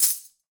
normal-hitwhistle.ogg